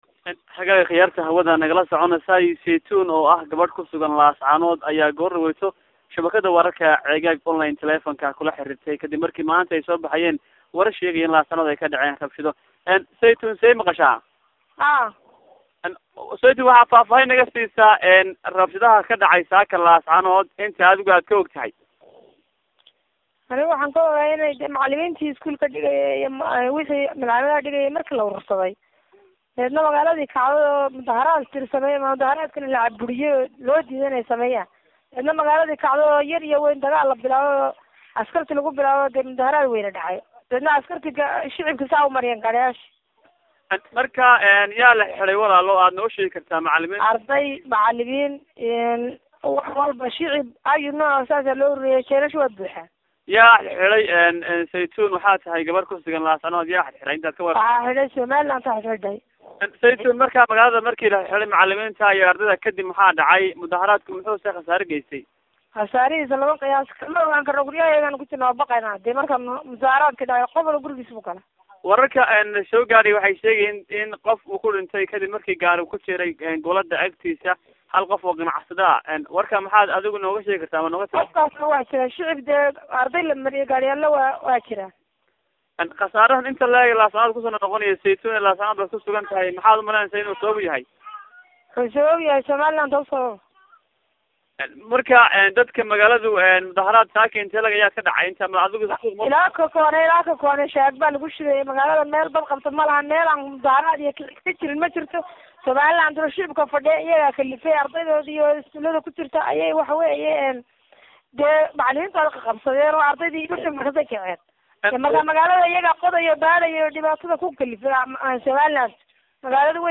Dibadbax rabshada Wata oo dadku dhinteen Qaarkalena ku dhaawacmeen oo Laascaanood ka dhacay (Waraysi Ceegaag Online layeelatay dadkii mudaharaadka waday oo nooga waramay Qaabka rabshadu ku bilaameen